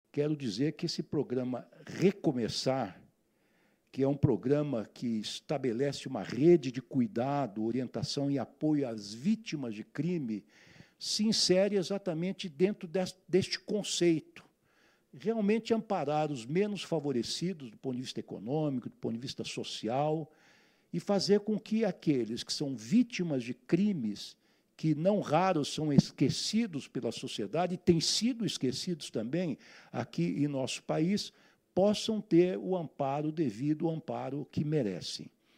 Ministro Ricardo Lewandowski fala mais sobre o propósito do Programa Recomeçar — Ministério da Justiça e Segurança Pública